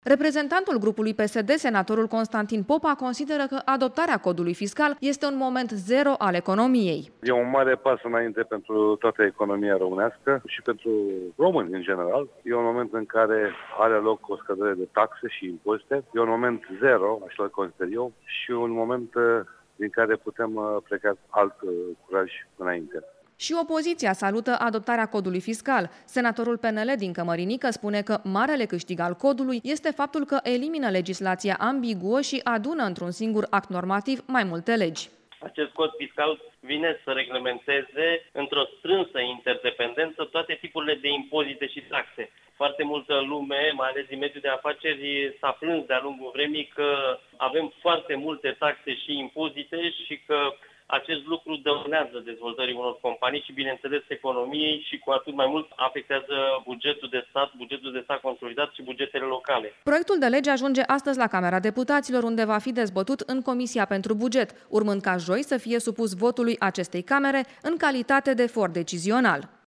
a participat la dezbaterile din plenul Senatului şi are detalii: